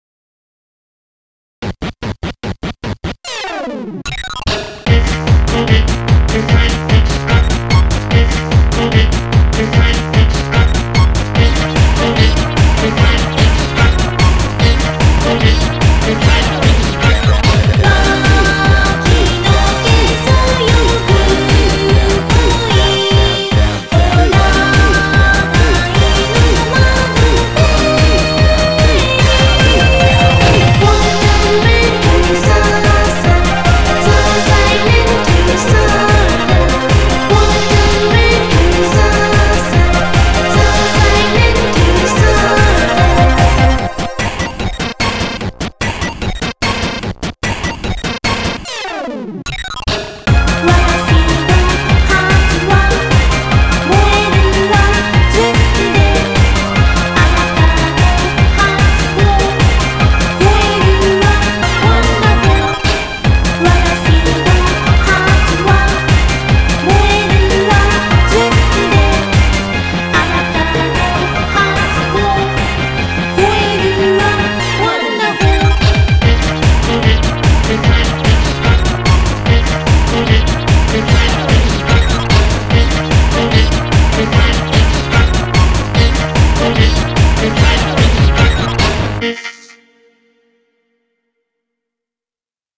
BPM148
Audio QualityPerfect (Low Quality)